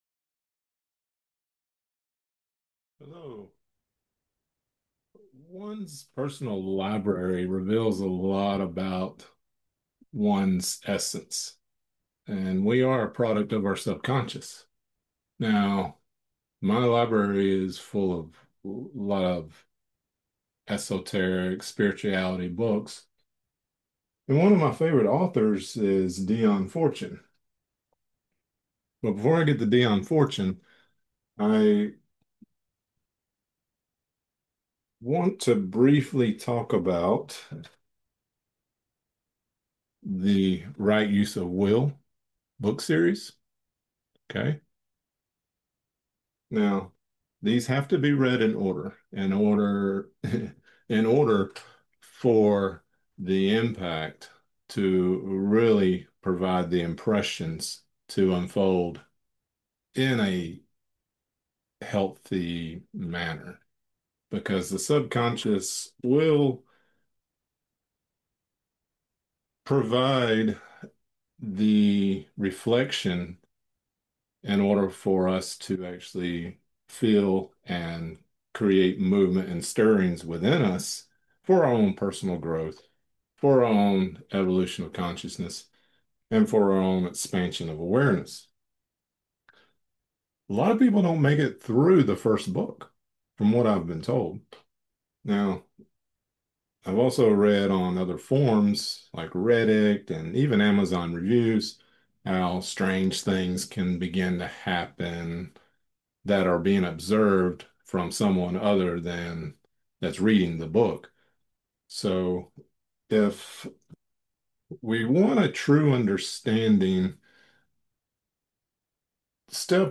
Lecture audio only